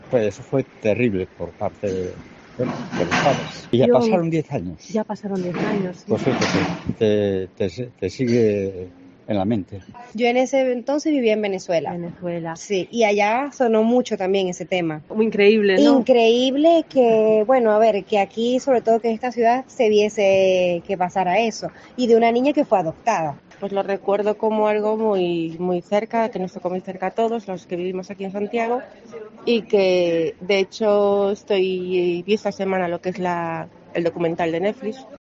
"Fue terrible por parte de los padres", explica en Cope Santiago un vecino de la zona. "Yo lo seguí desde Venezuela", nos cuenta una mujer que recuerda que en su país tuvo también repercusión el crimen.